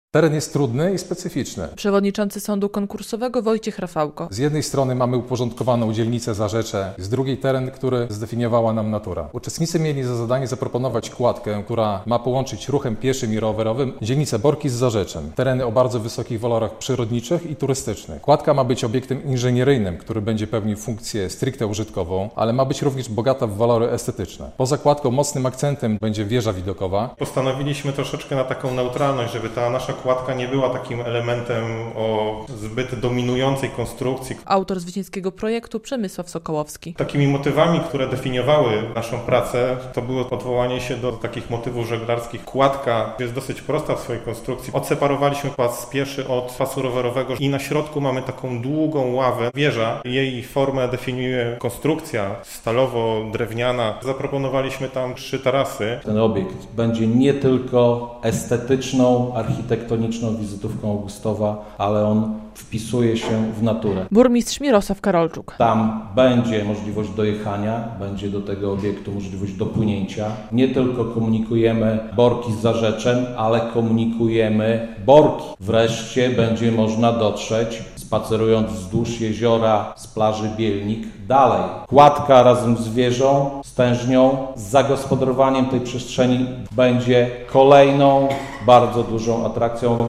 W Augustowie powstanie kładka łącząca brzegi Netty - relacja